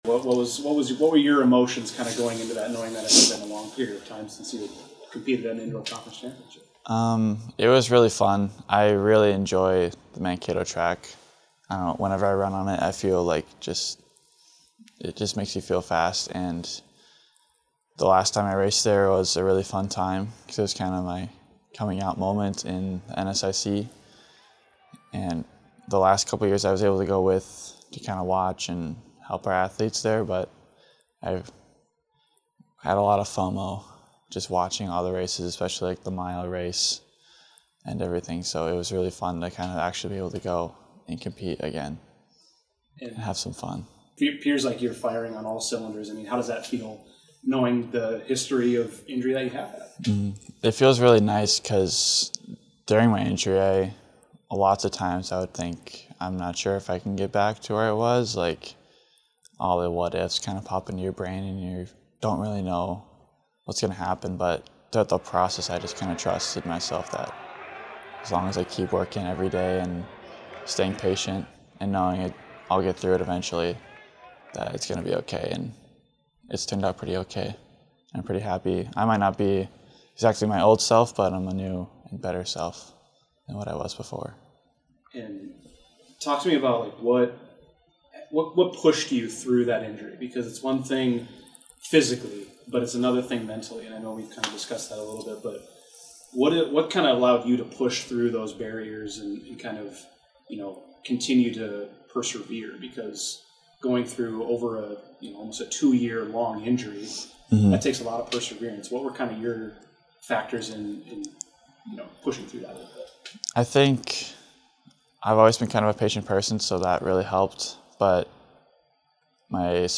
interview about his return to the NCAA Indoor National Championships